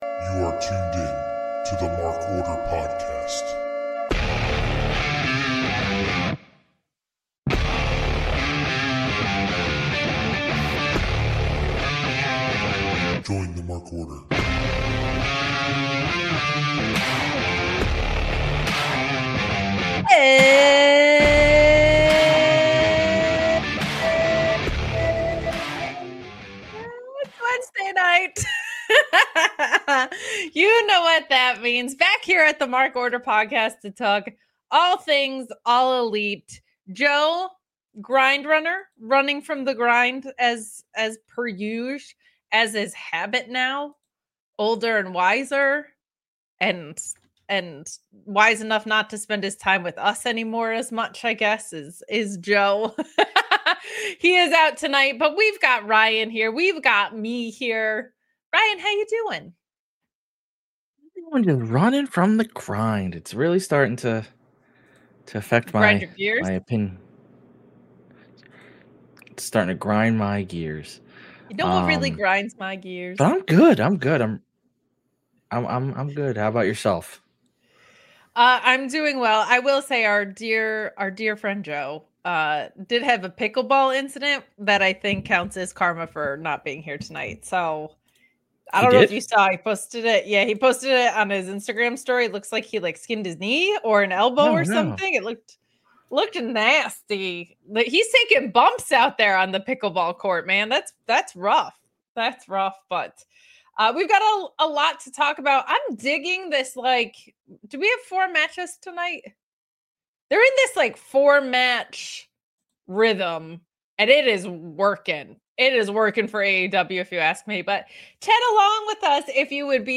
The duo talks about another great Dynamite episode.